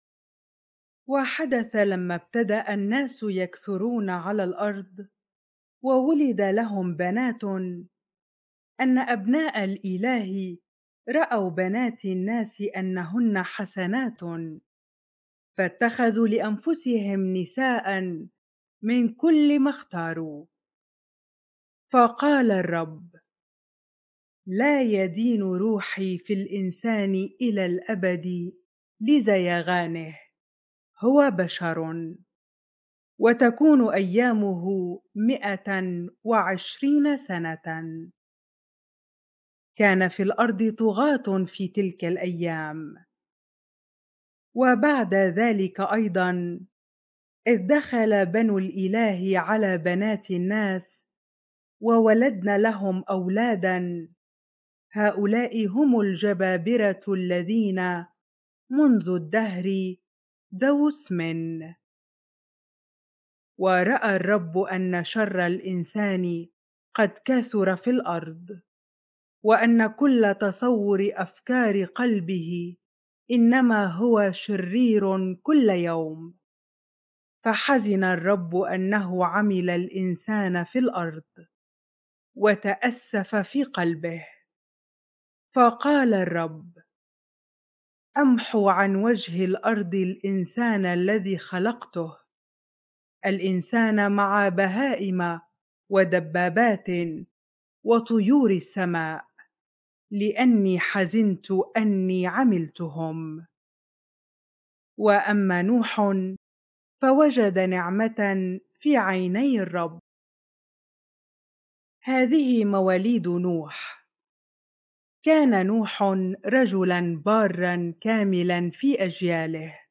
bible-reading-genesis 6 ar